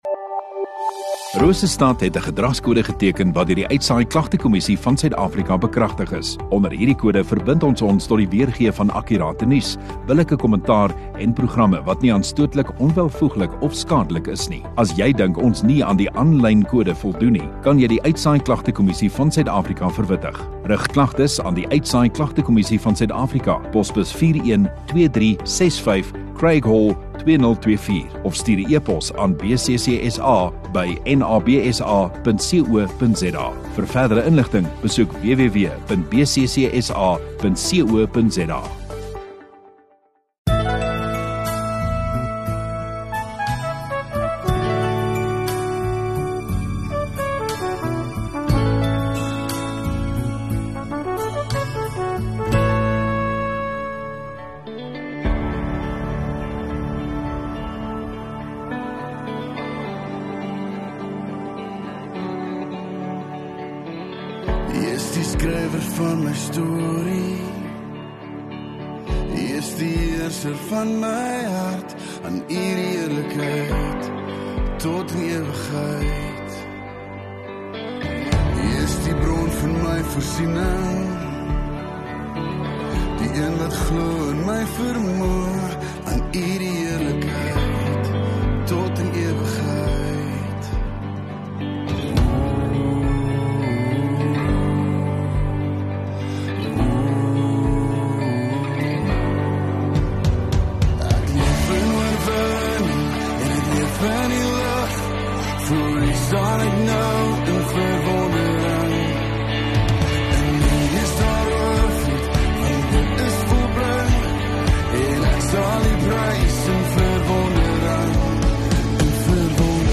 13 Dec Vrydag Oggenddiens